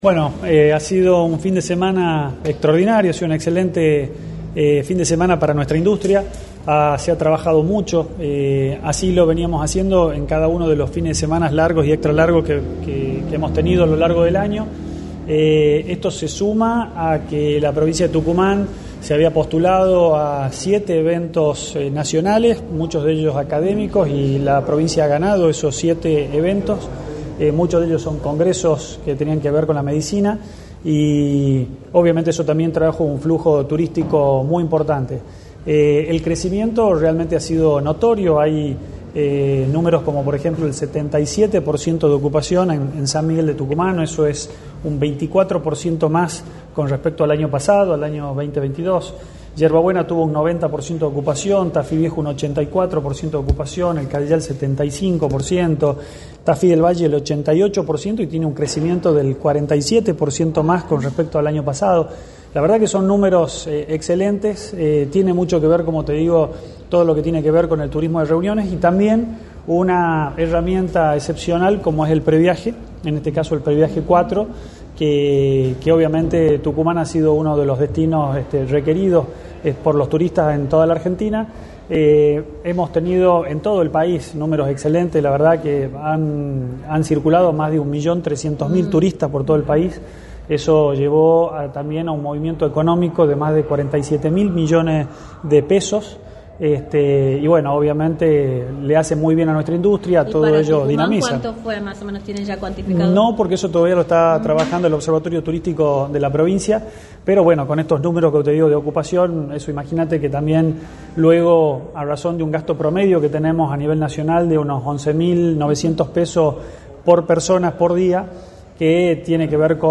«Fue un fin de semana extraordinario para nuestra industria, esto tiene mucho que ver con el turismo de reuniones y con el Previaje 4 ya que Tucumán ha sido uno de los destinos más elegidos por los turistas de todo el país» remarcó el titular del Ente de turismo, Sebastián Giobellina, en entrevista para Radio del Plata Tucumán, por la 93.9.